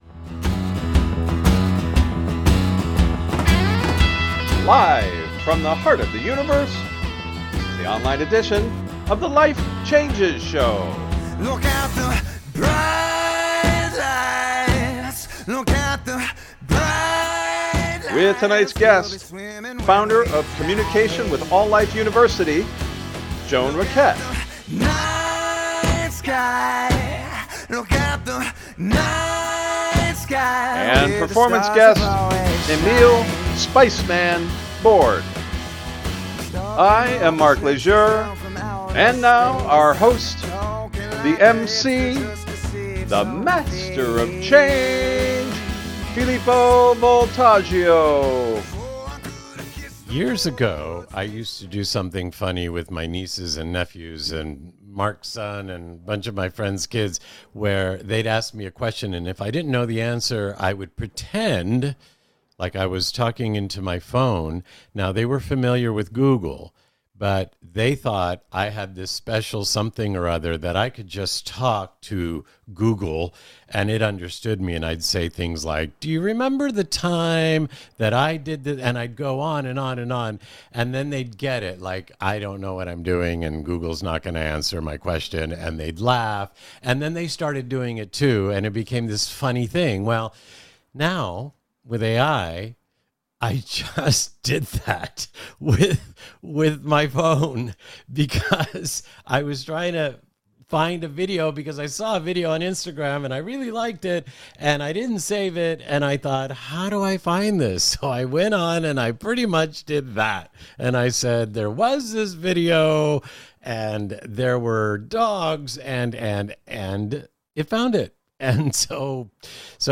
Interview Guest
Performance Guest, Trinidadian Steel Drummer